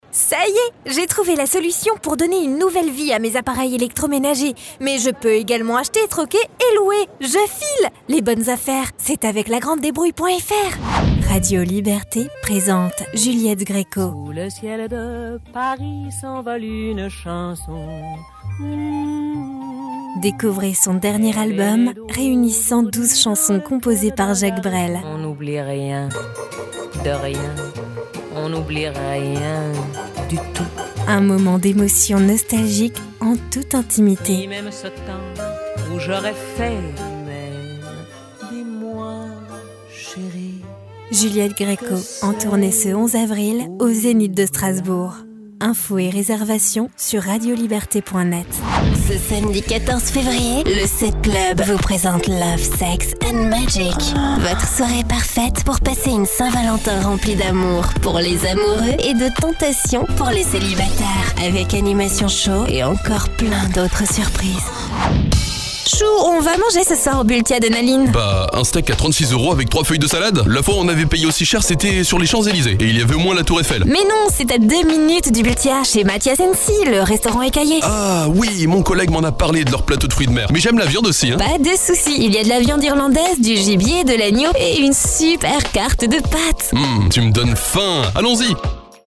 Sprechprobe: Werbung (Muttersprache):
French Voice Over Talent